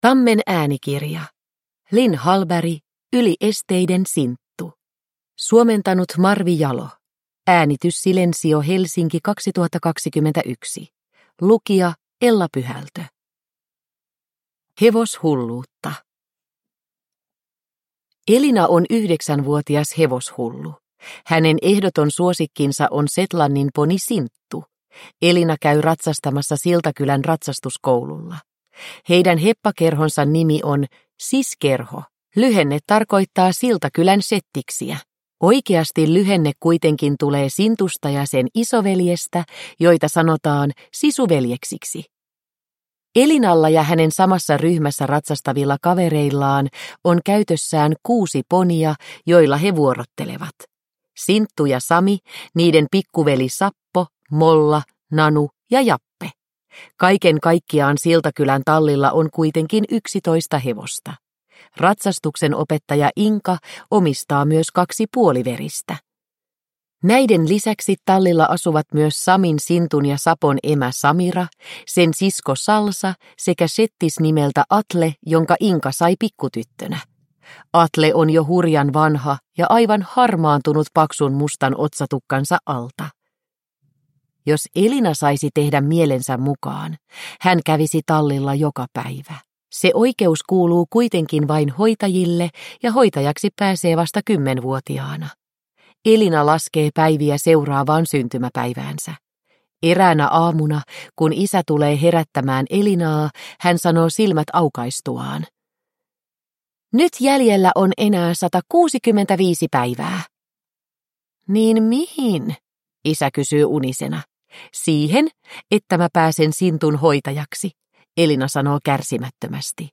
Yli esteiden, Sinttu – Ljudbok